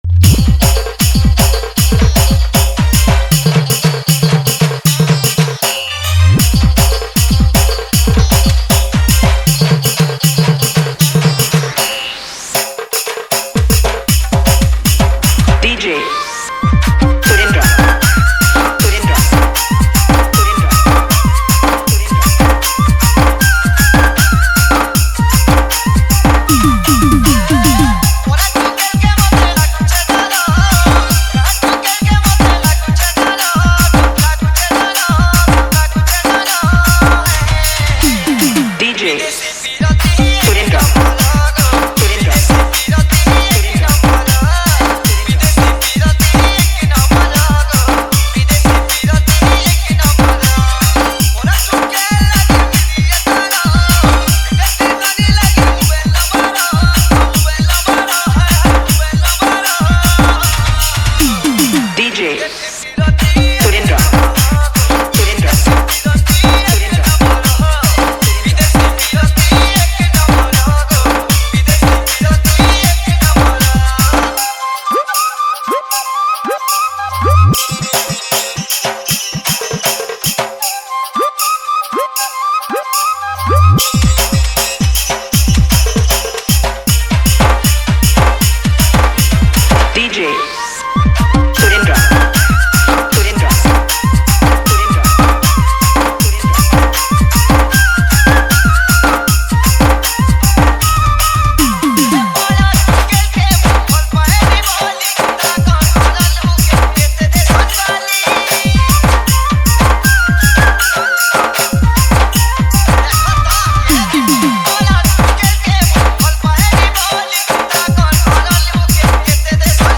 Category:  Old Sambalpuri Dj Song